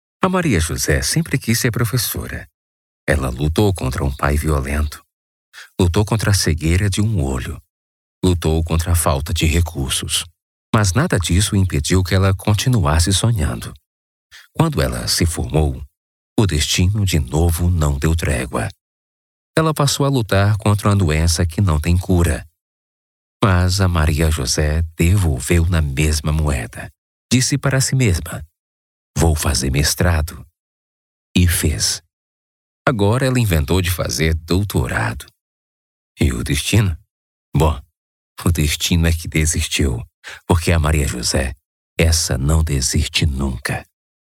Demo Off - Institucional: